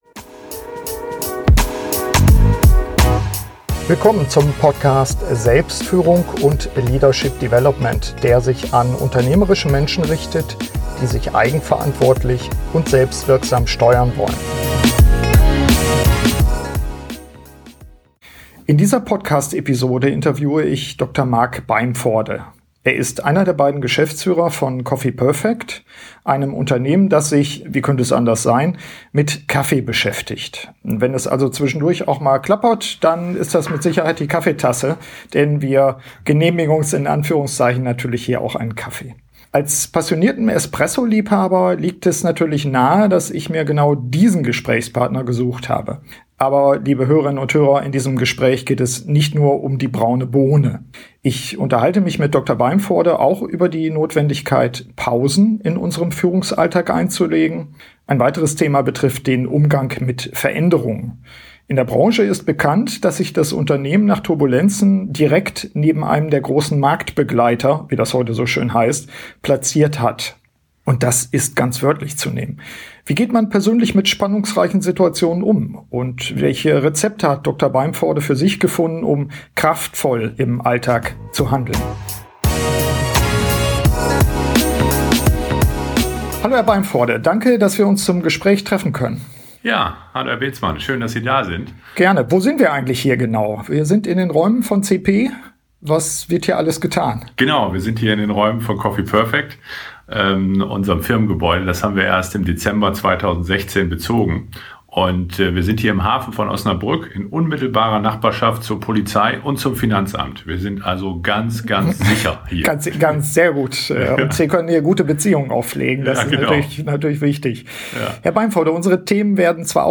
In dieser Podcast-Episode interviewe ich